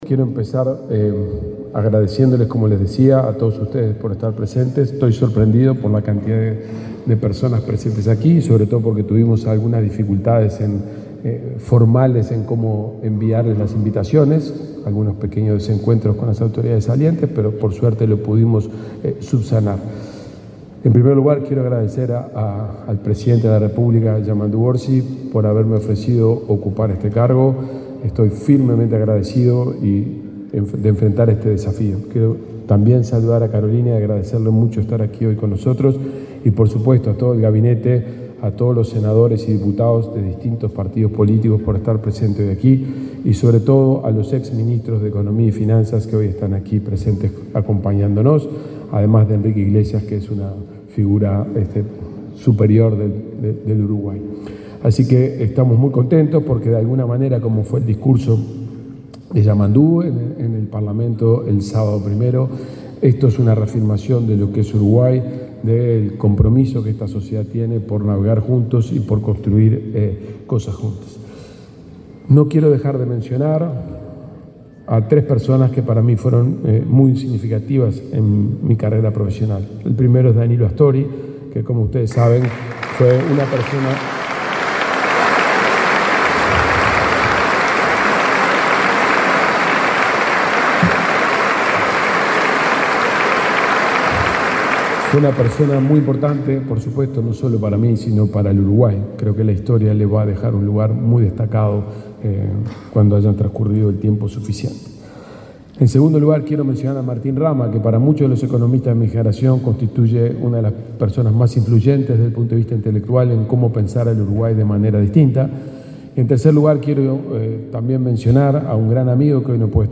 Palabras del ministro de Economía y Finanzas, Gabriel Oddone
Palabras del ministro de Economía y Finanzas, Gabriel Oddone 05/03/2025 Compartir Facebook X Copiar enlace WhatsApp LinkedIn El presidente de la República, profesor Yamandú Orsi, y la vicepresidenta, Carolina Cosse, asistieron al acto de asunción de las autoridades del Ministerio de Economía y Finanzas, Gabriel Oddone, como ministro, y Martín Vallcorba como subsecretario.